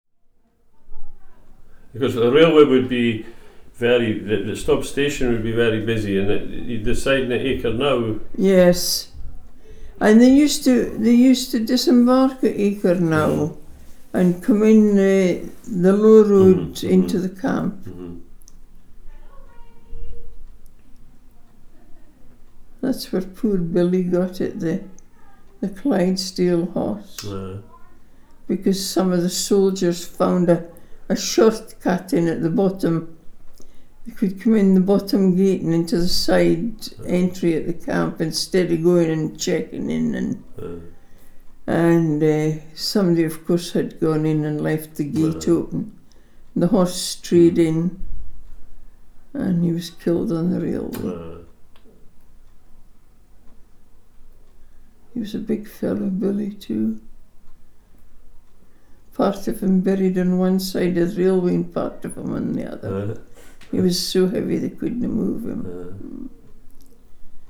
Oral History Interviews - Stobs Military Camp Hawick Scottish Borders